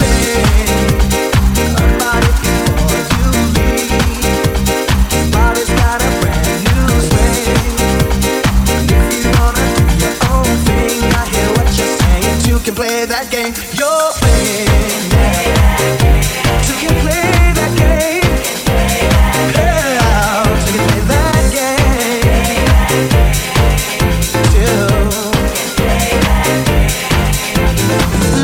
piano house classics
Genere: house, piano house, anni 90, successi, remix